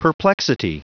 Prononciation du mot perplexity en anglais (fichier audio)
Prononciation du mot : perplexity